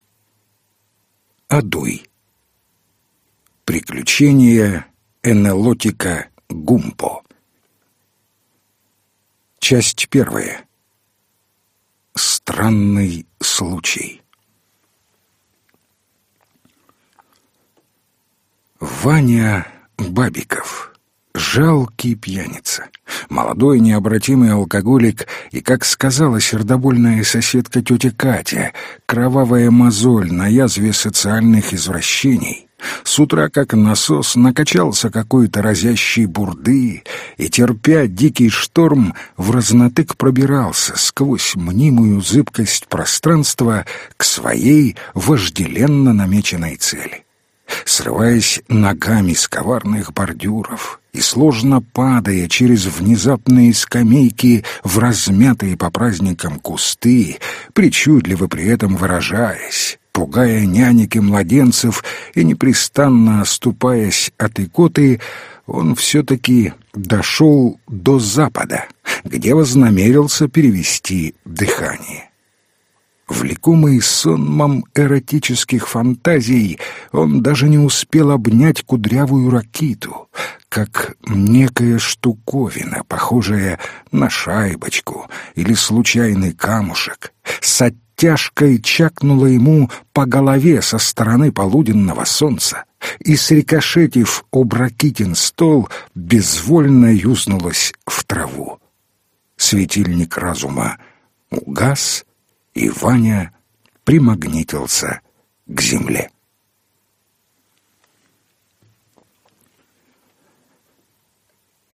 Аудиокнига Приключения энэлотика Гумпо | Библиотека аудиокниг